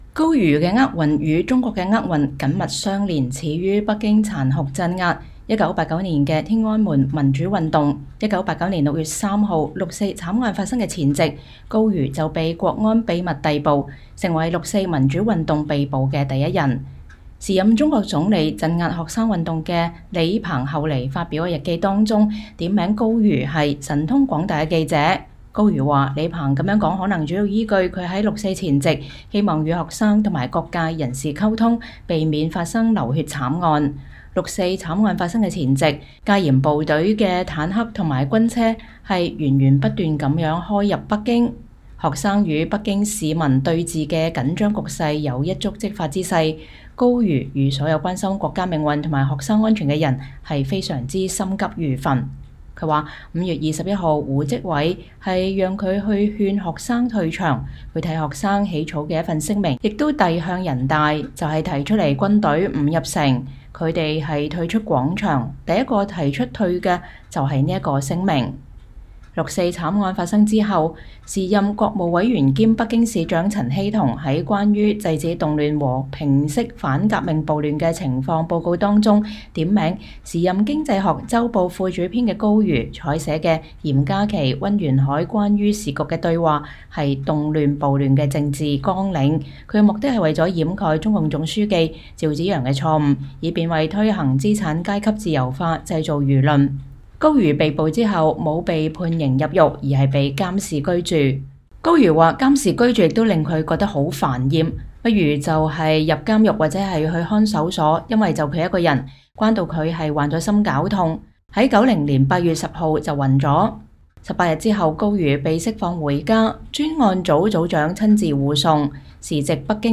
專訪高瑜：歷盡磨難 雖九死其猶未悔